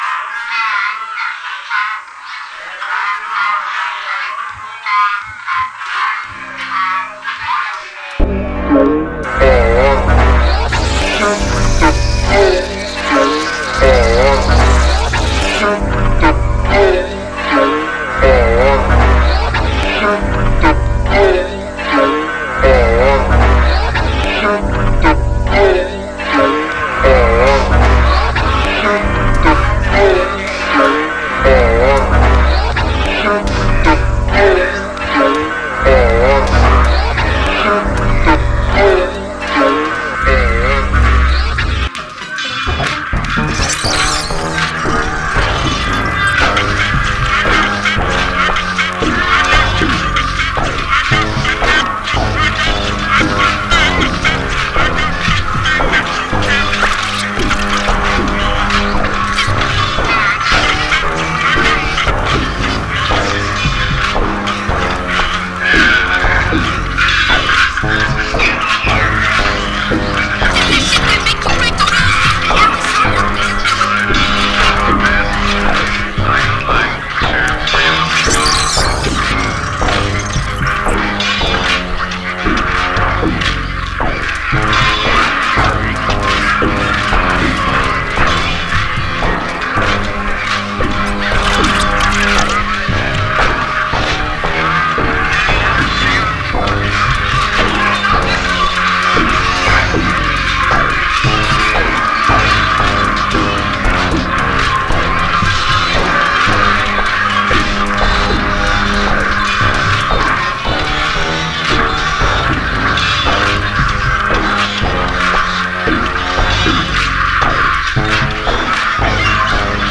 shitcore